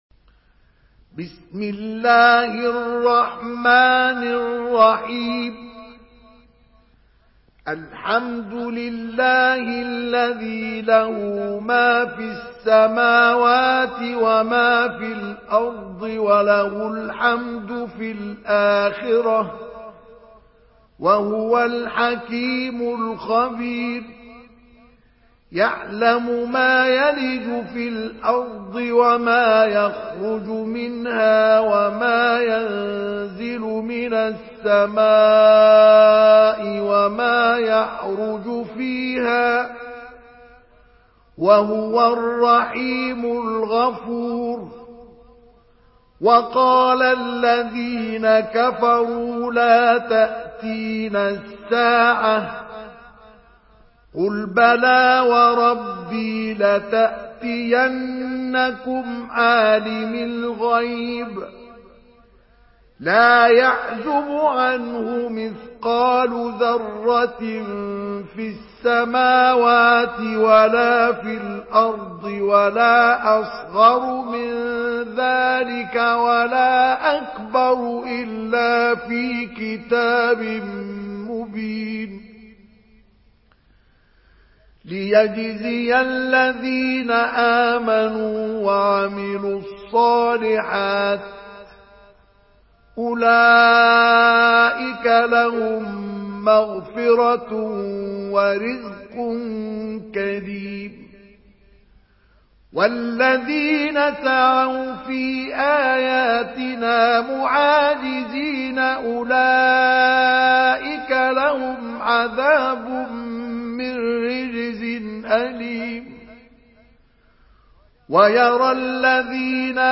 Surah سبأ MP3 by مصطفى إسماعيل in حفص عن عاصم narration.
مرتل